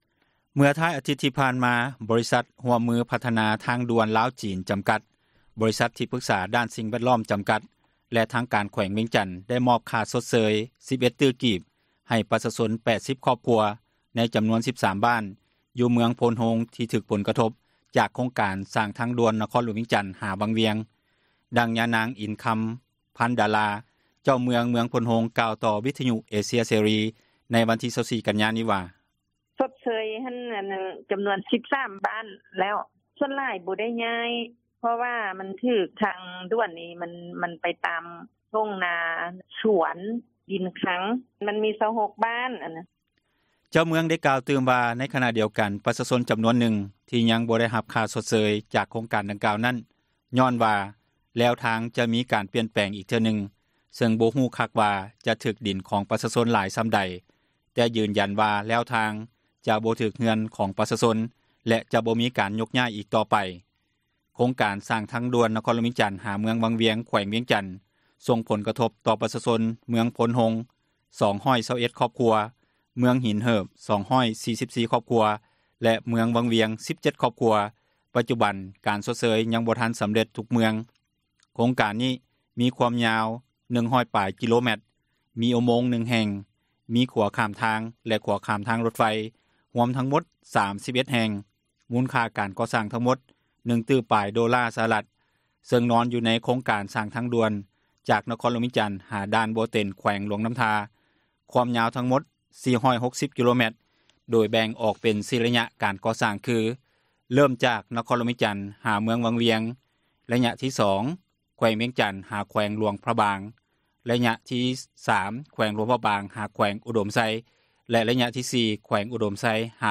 ດັ່ງ ຍານາງ ອິນຄຳ ພັນດາລາ ເຈົ້າເມືອງ ເມືອງໂພນໂຮງ ກ່າວຕໍ່ ວິທຍຸເອເຊັຍເສຣີ ໃນວັນທີ 24 ກັນຍາ ນີ້ວ່າ: